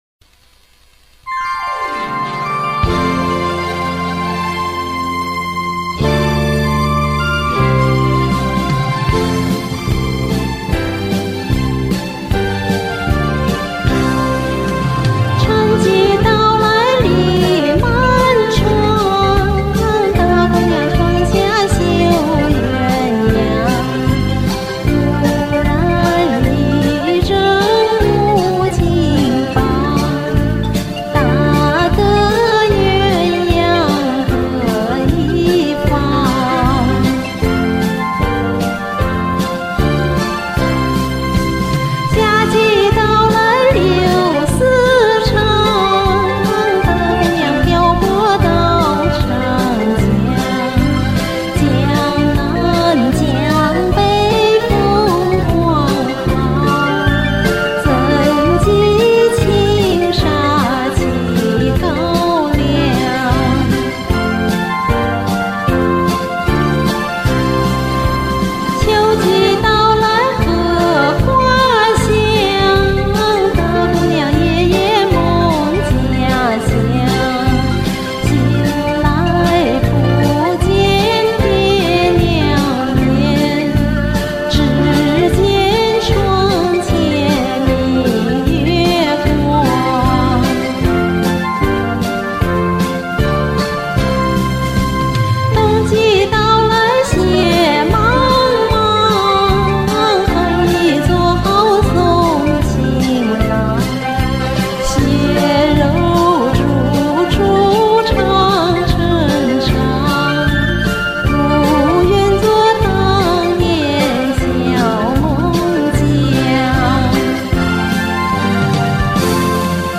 聲音清麗柔美太動聽了
聲音圓潤甜美靚麗，好